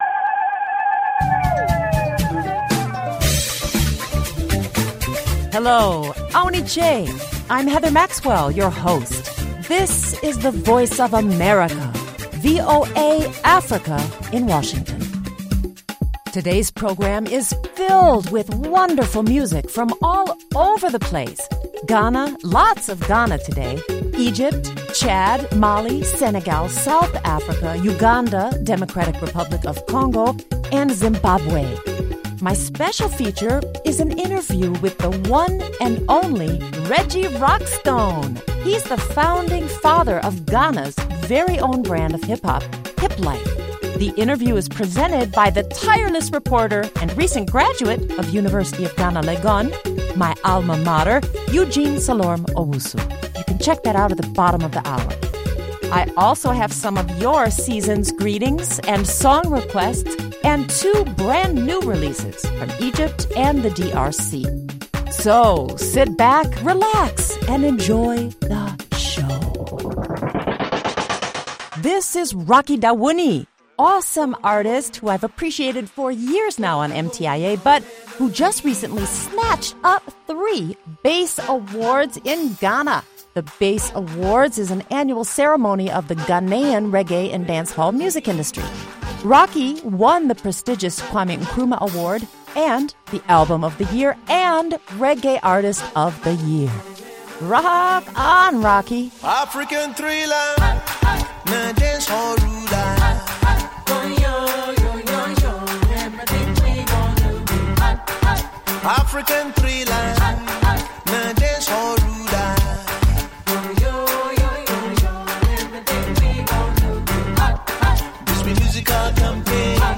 exclusive interviews
rare recordings